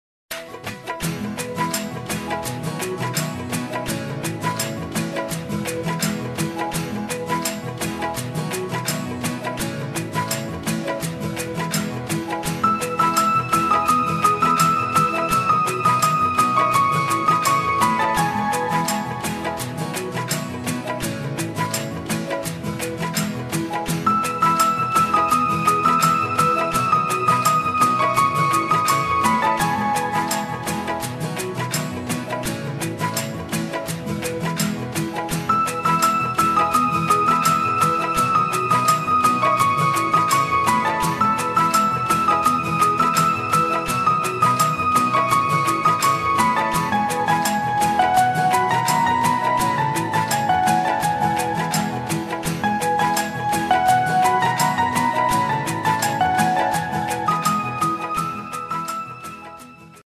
Categories TV Serials Tones